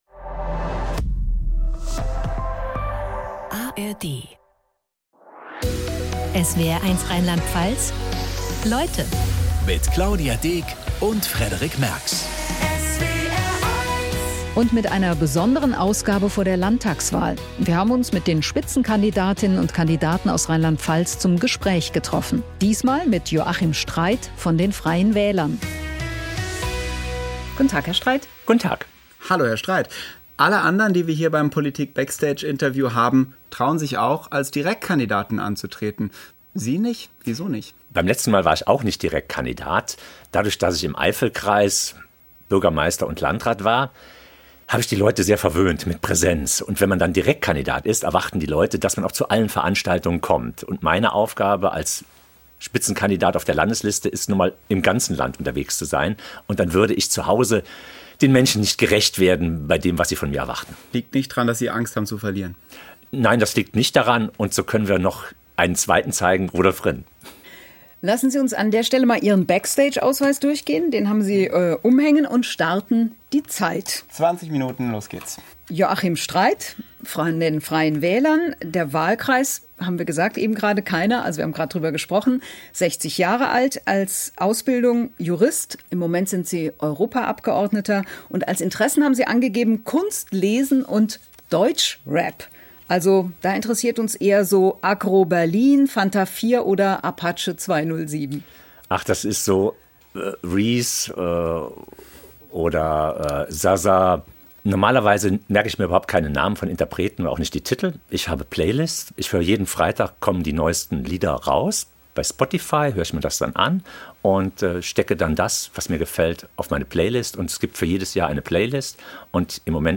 Anlässlich der Landtagswahl 2026 in Rheinland-Pfalz sendet der SWR multimediale Interviews mit den Spitzenkandidatinnen und -kandidaten von SPD, CDU, Grünen, AfD, Freien Wählern, Linken und FDP.